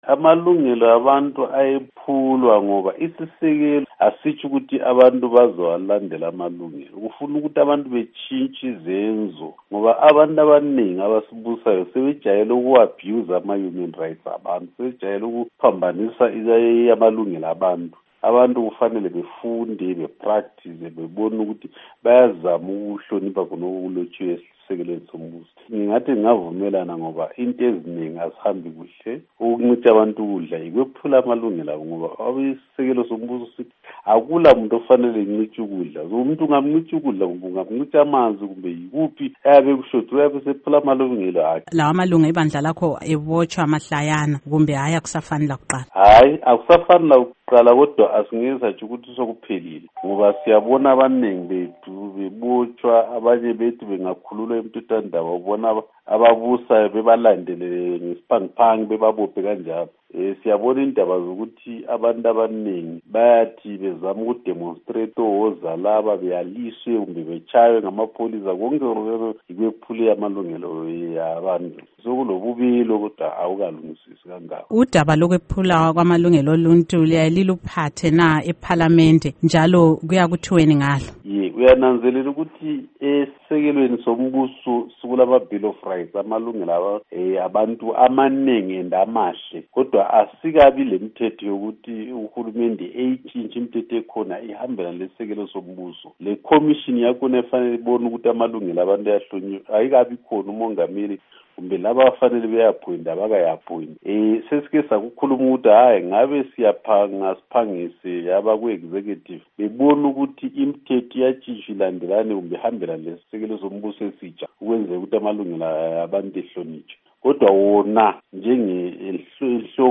Ingxoxo LoMnu. Joel Gabhuza